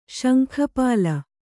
♪ śankha pāla